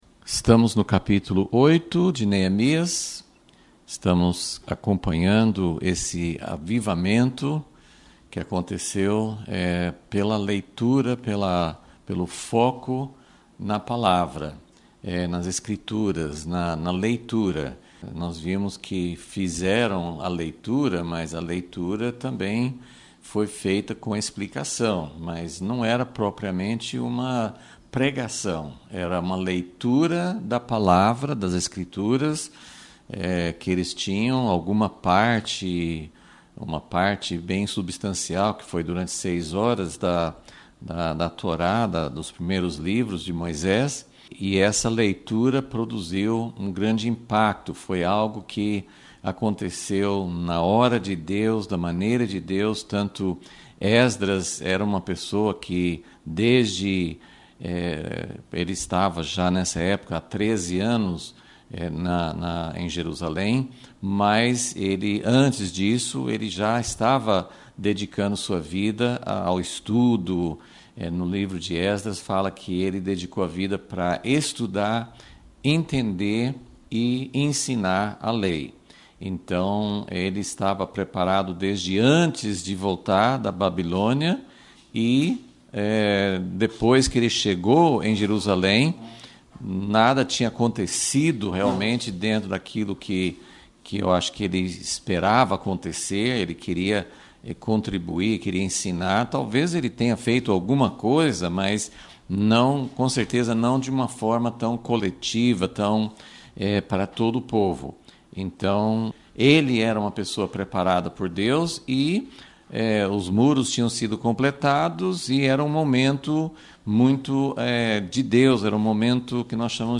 Aula 21 - Vol.36 - Quando a alegria do Senhor pode ser nossa força — Impacto Publicações